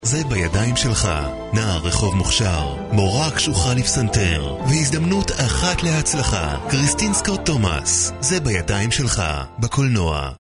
Native speaker Male 20-30 lat
Demo lektorskie
Spot radiowy